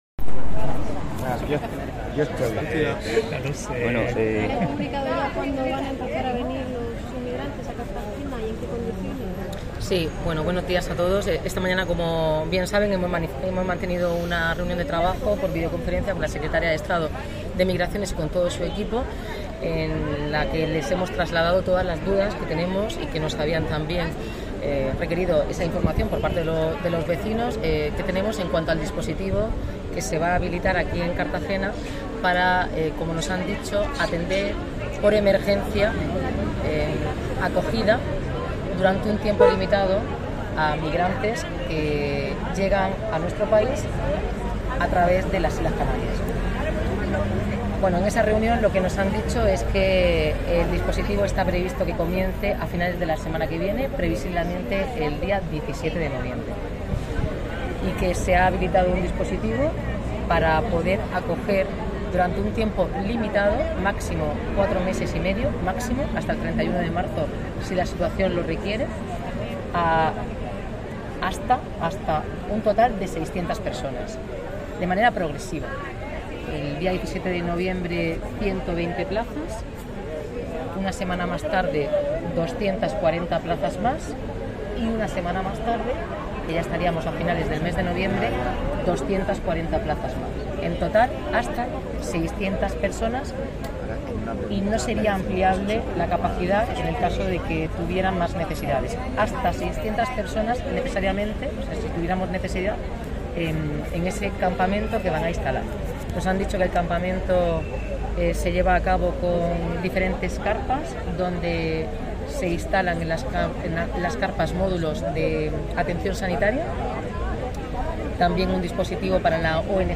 Audio: Declaraciones de la alcaldesa, Noelia Arroyo. (MP3 - 14,88 MB)